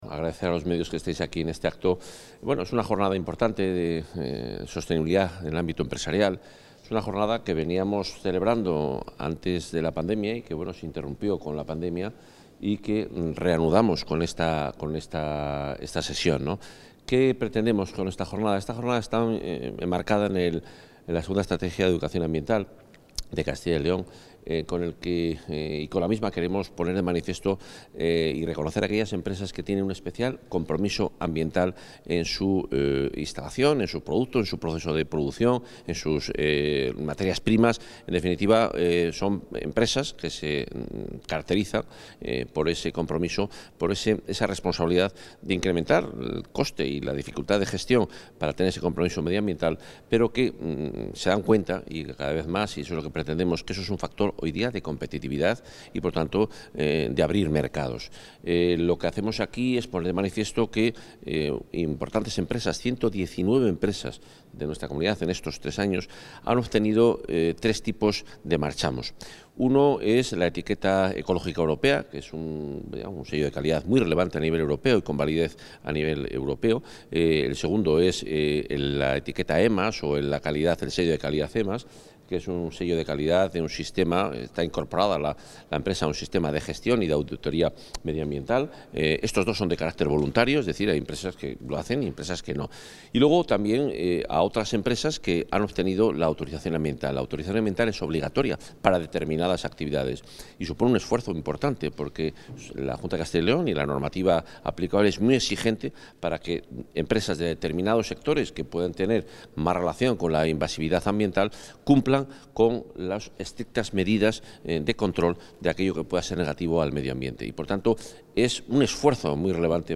Intervención del consejero.
El consejero de Medio Ambiente, Vivienda y Ordenación del Territorio, Juan Carlos Suárez-Quiñones, ha presidido el acto de entrega de placas de reconocimiento a 116 empresas de Castilla y León por su esfuerzo en la gestión ambiental durante los últimos tres años, empresas que voluntariamente han implantado el Sistema de Gestión y Auditoría Medioambientales EMAS, que han adherido sus productos a la Etiqueta Ecológica Europea y que han obtenido su autorización ambiental. El acto se ha celebrado en el transcurso de la jornada ‘Sostenibilidad en empresas’, como encuentro para reconocer su esfuerzo